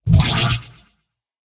gravity_gun_drop.wav